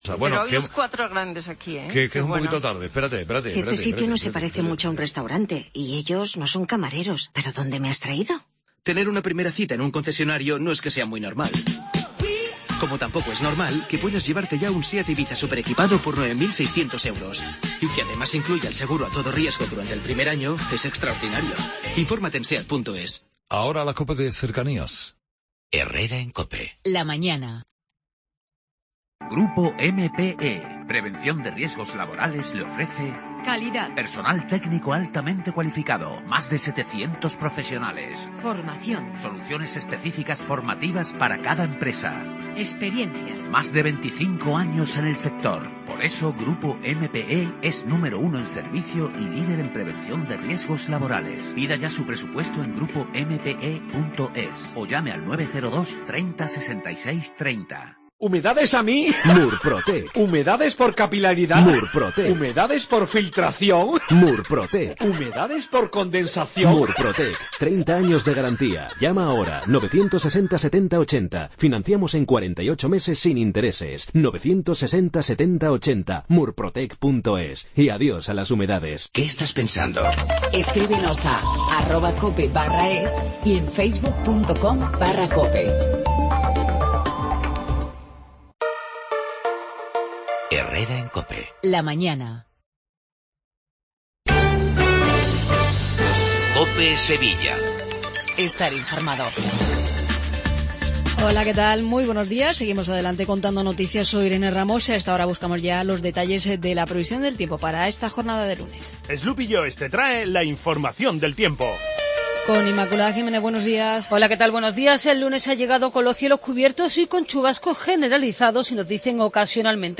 INFORMATIVO LOCAL MATINAL 8:20